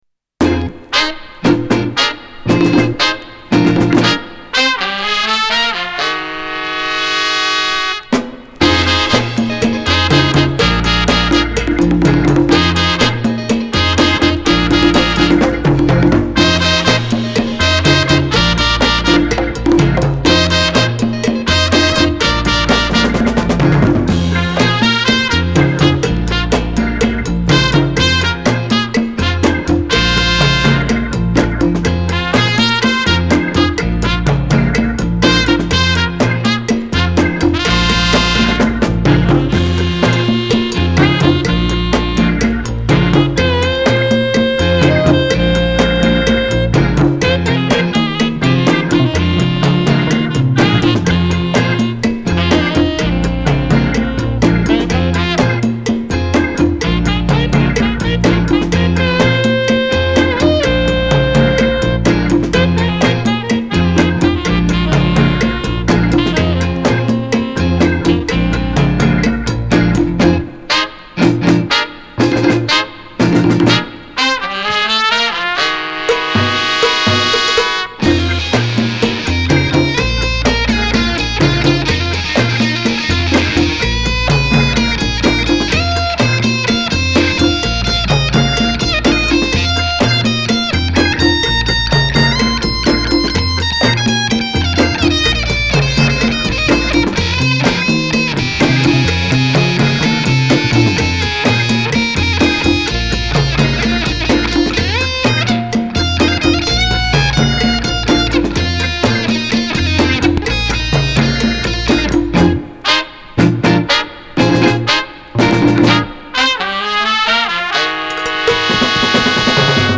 horn solos
epic fuzz guitar noodling